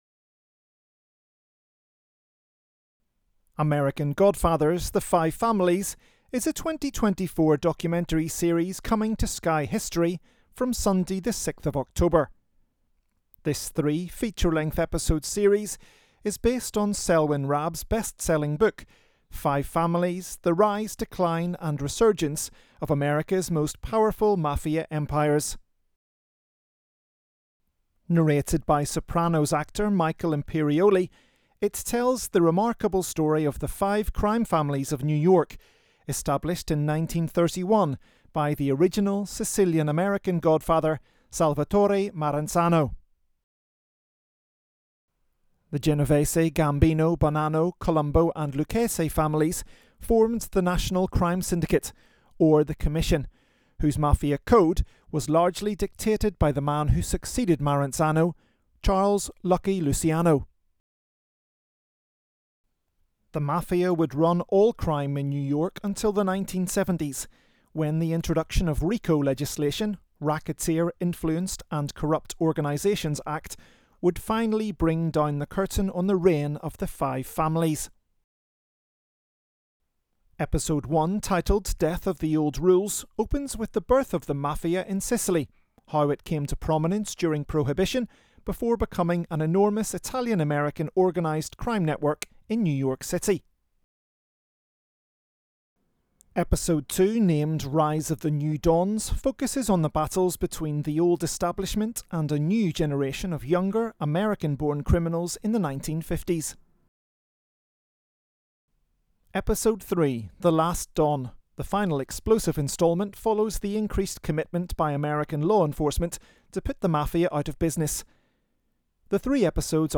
AD Introduction for 'American Godfathers, the Five Families'